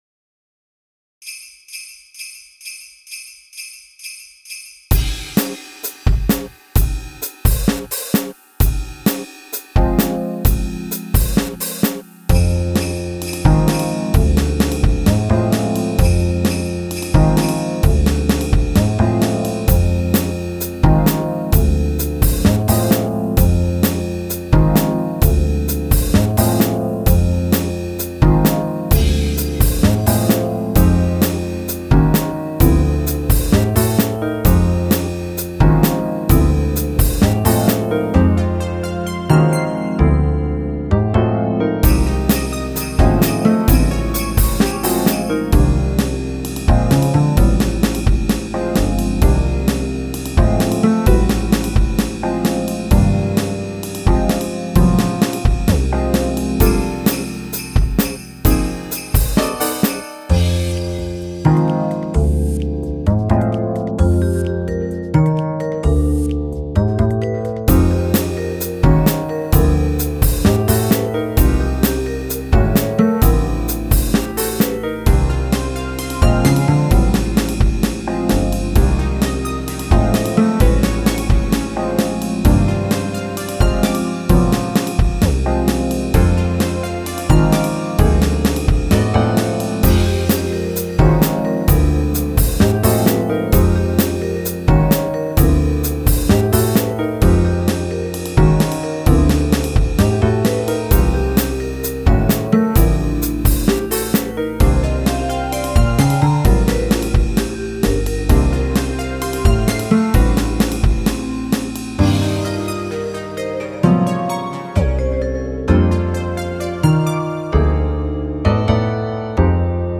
Genre Jazz